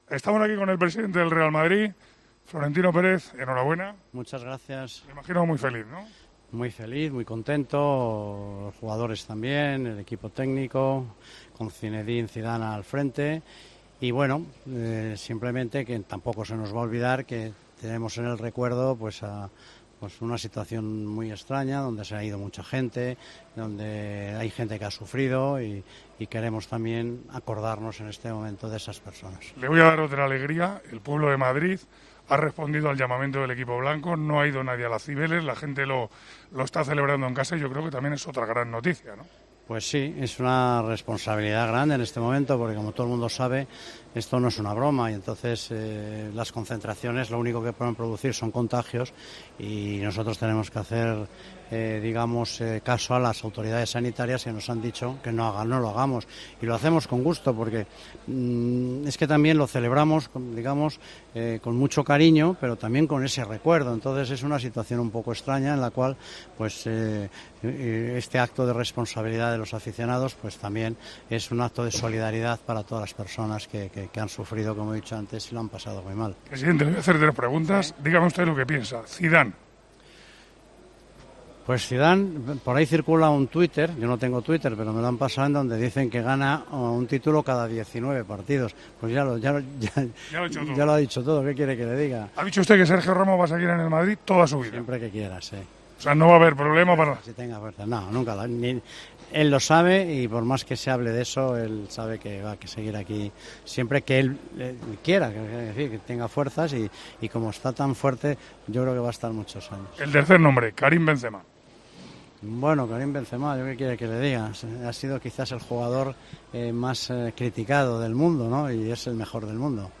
Manolo Lama entrevistó al presidente blanco tras ganar su 34ª Liga: "Zidane gana un título cada 19 partidos, eso lo dice todo. Benzema es el más criticado y es el mejor del mundo".
"Sergio Ramos seguirá en el Real Madrid siempre que quiera, él lo sabe. Siempre que quiera y tenga fuerzas, y como tiene fuerzas, creo que estará muchos años con nosotros", dijo Florentino Pérez este jueves en El Partidazo de COPE, en la entrevista con Manolo Lama en el Alfredo Di Stéfano tras la conquista de la 34ª Liga del Real Madrid.